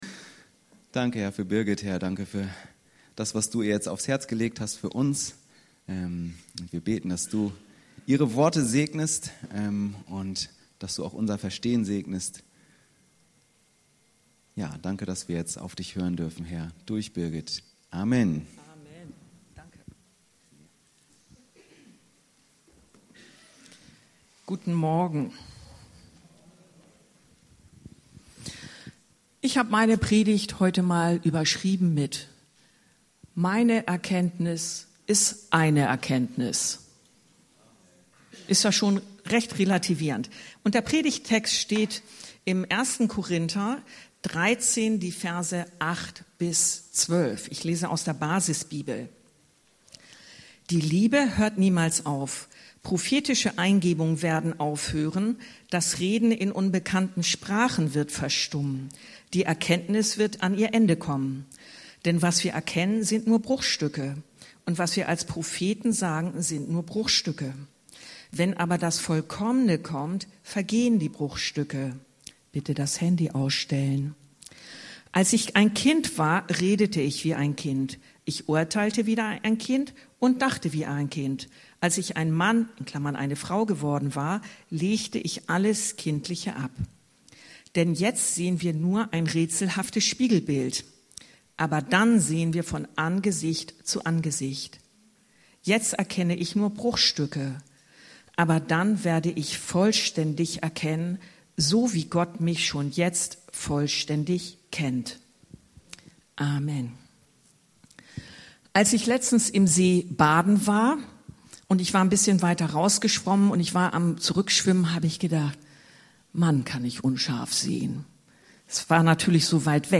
Meine Erkenntnis ist eine Erkenntnis ~ Anskar-Kirche Hamburg- Predigten Podcast